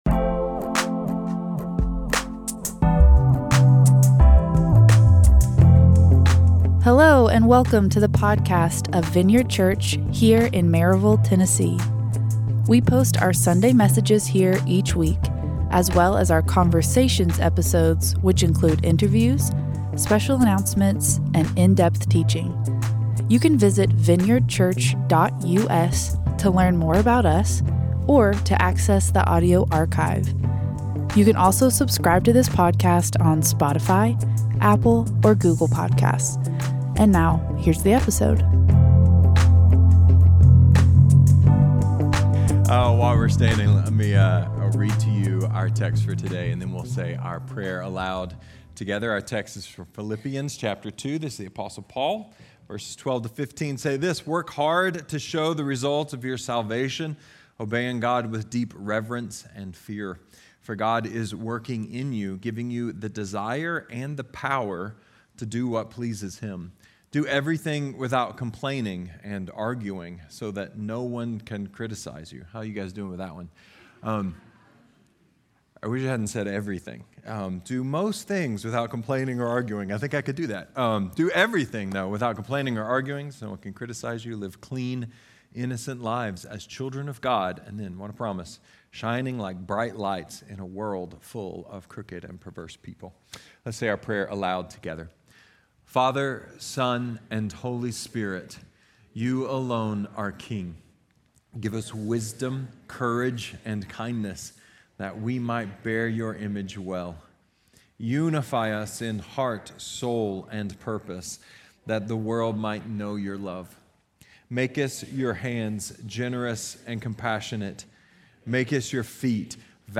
A sermon about saints, heroes, superheroes, and revival!